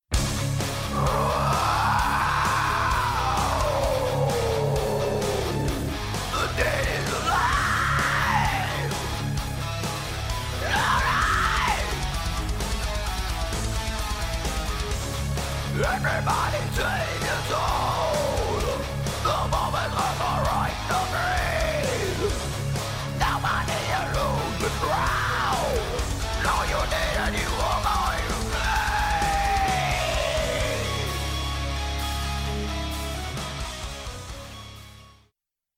Metal
Screaming, Death Metal